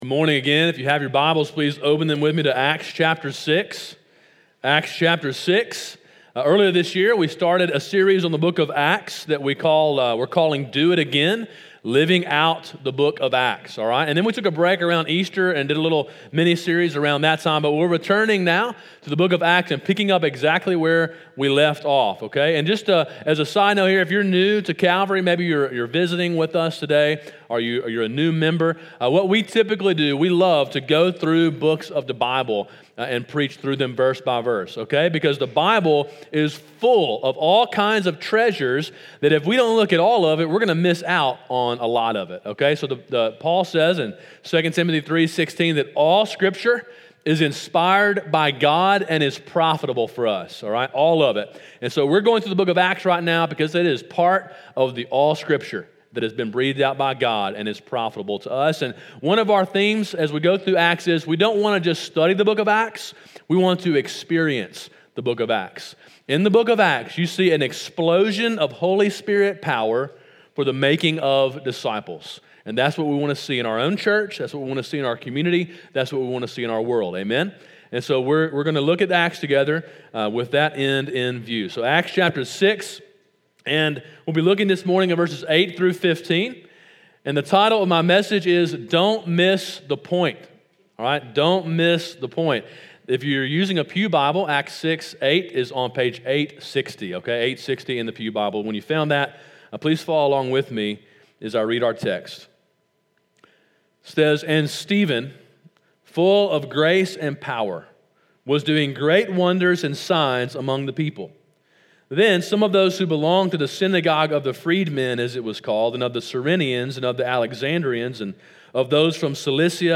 Sermon: “Don’t Miss the Point” (Acts 6:8-15)